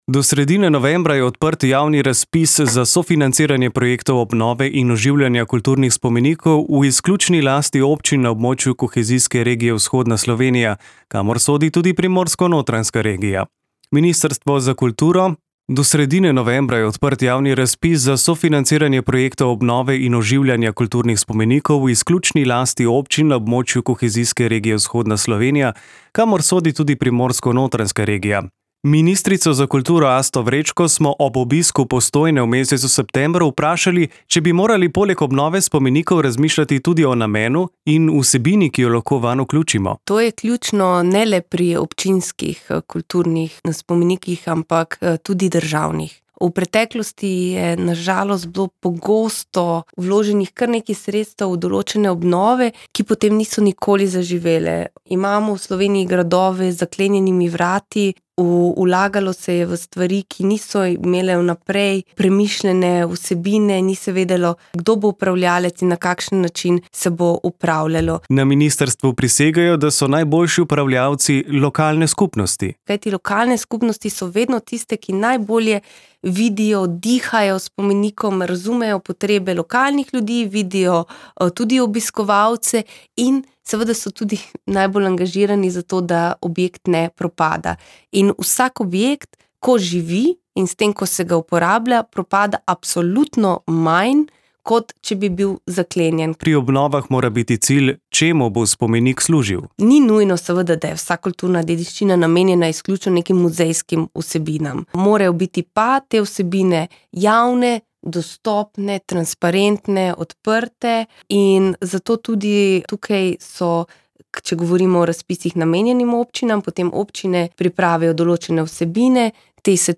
Do sredine novembra je odprt javni razpis za sofinanciranje projektov obnove in oživljanja kulturnih spomenikov v izključni lasti občin na območju kohezijske regije Vzhodna Slovenija, kamor sodi tudi primorsko-notranjska regija. O tem smo se pogovarjali z ministrico za kulturo Asto Vrečko. Poudarila je, da usmeritve evropskih in mednarodnih organizacij stremijo k obnovi in prenovi, ne k novogradnji.